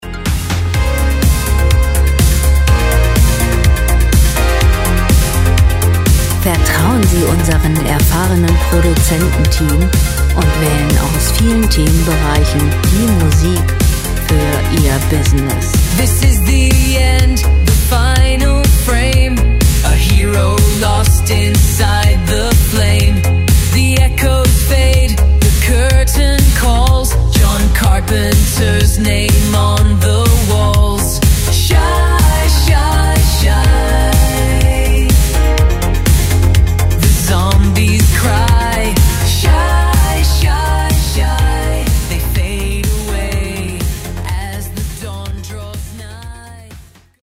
gemafreie Pop Musik
Musikstil: Synthwave
Tempo: 124 bpm
Tonart: F-Moll
Charakter: up-to-date, jugendlich
Instrumentierung: Popsänger, Synthesizer